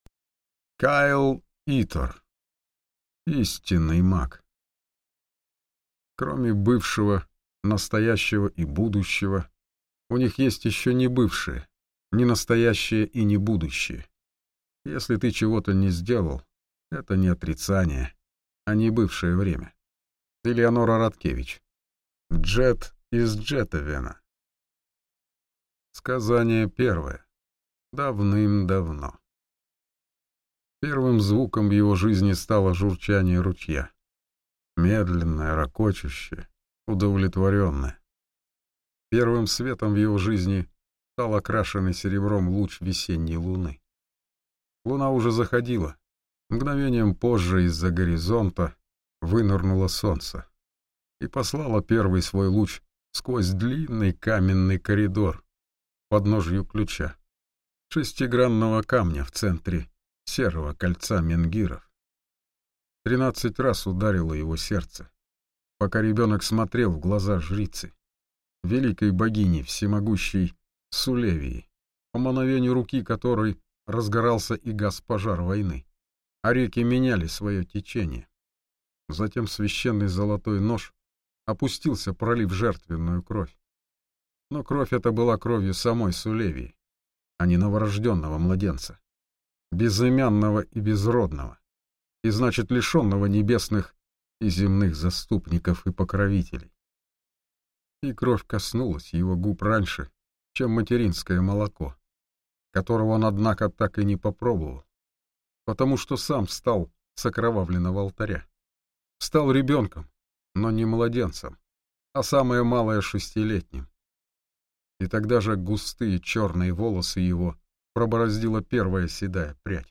Аудиокнига Истинный маг | Библиотека аудиокниг